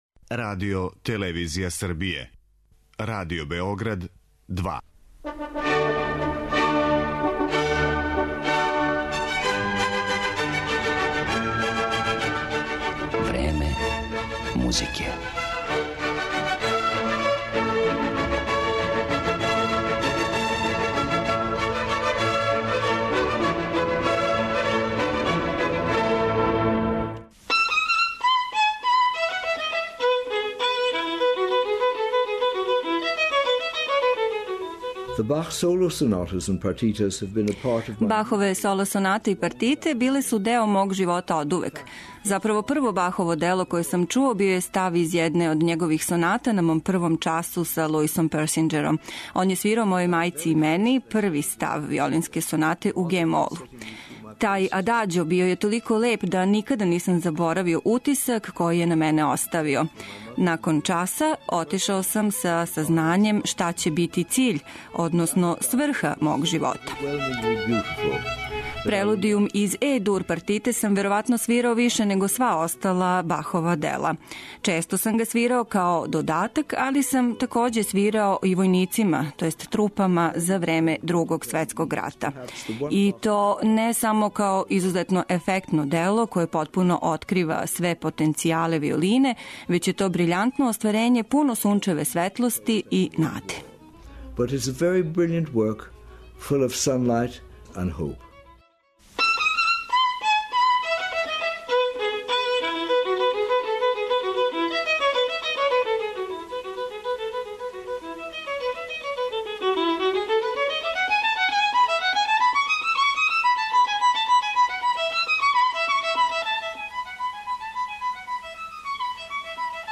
У интерпретацији овог виолинисте, који је у музици искрено уживао свирајући и класичан виолински репертоар, али и музицирајући са Грапелијем или са индијским уметником на ситару Равијем Санкаром, данас ћете имати прилику да чујете дела Баха, Моцарта, Бетовена, Бартока и Волтона.